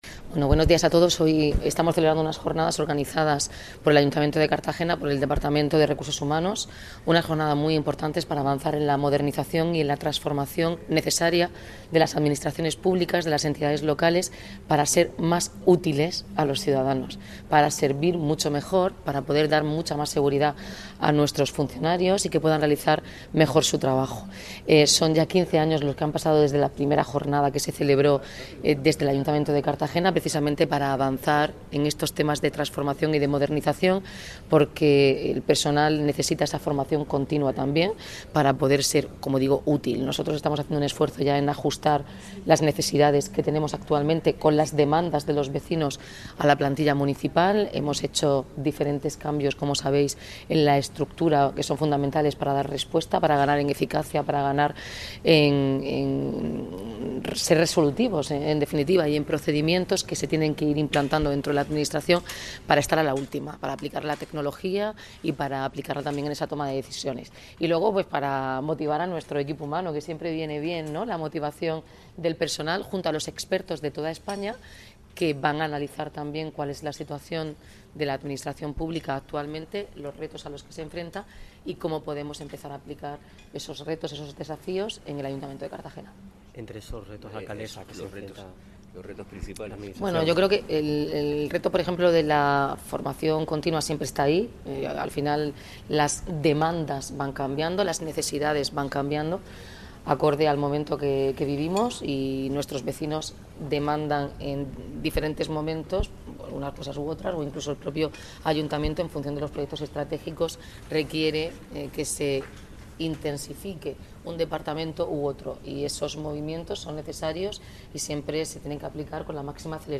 Enlace a Declaraciones de la alcaldesa, Noelia Arroyo, sobre Jornada de RR HH en Ayuntamientos